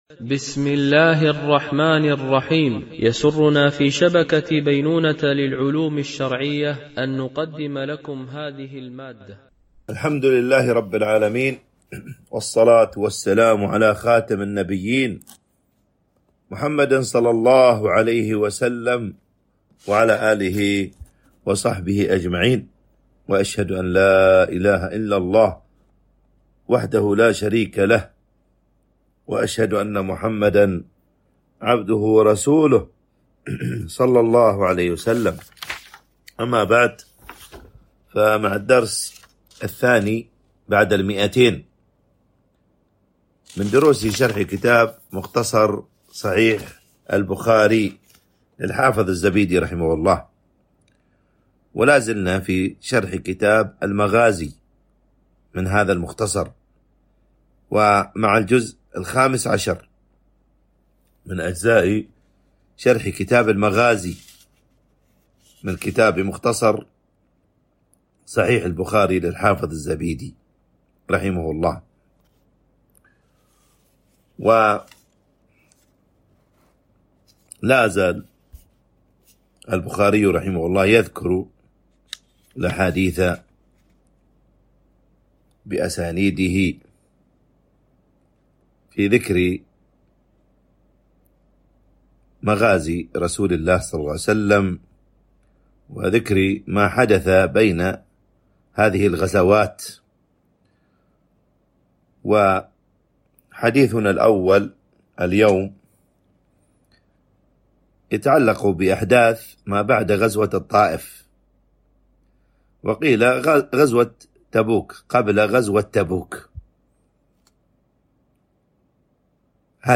شرح مختصر صحيح البخاري ـ الدرس 202 ( كتاب المغازي ـ الجزء الخامس عشر- الحديث 1680 - 1685)